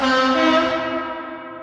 deltic_honk_1.ogg